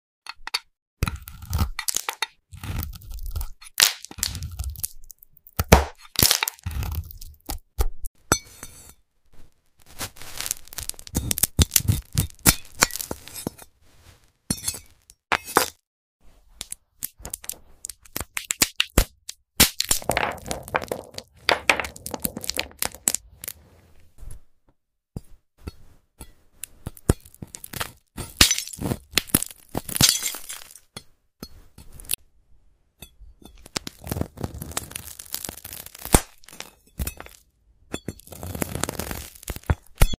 Glass geode fruit cutting 🌟💎🔪 sound effects free download